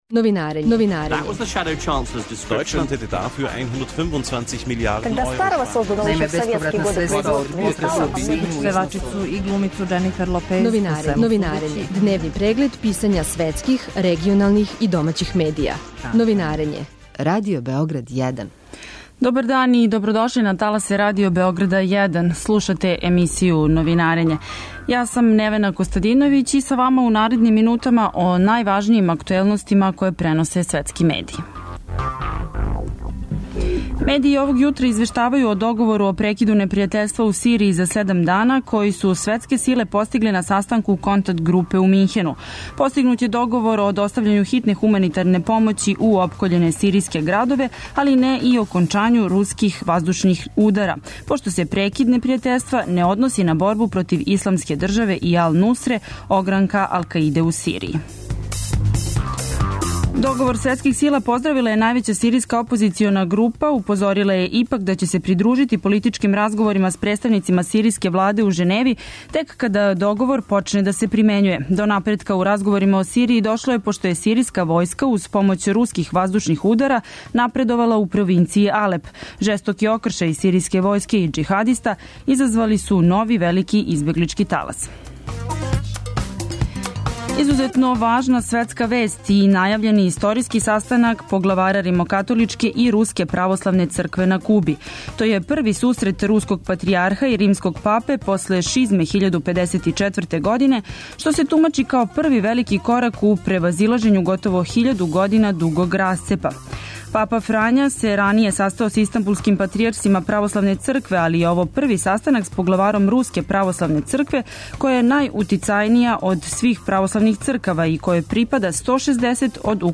Гост у студију је бивши амбасадор Србије у Ватикану и професор Филолошког факултета Владета Јанковић.